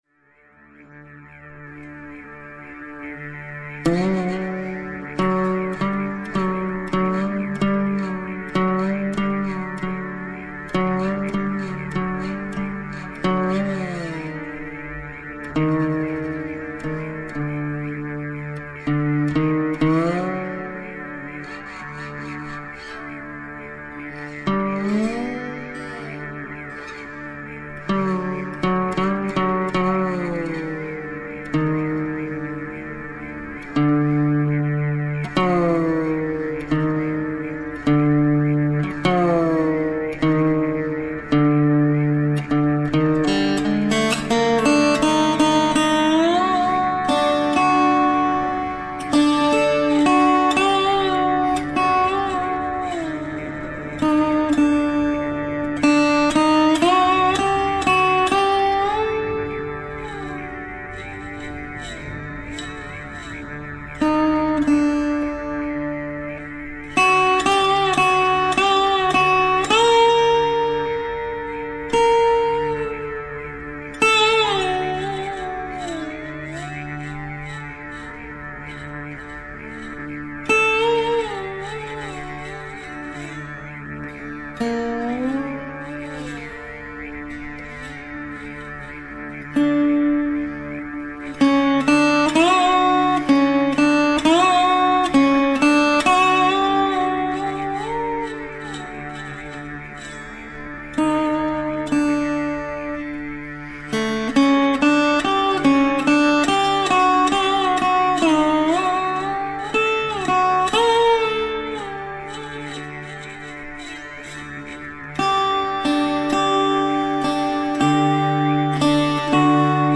Mohan Vina
Banjo / Throat Singing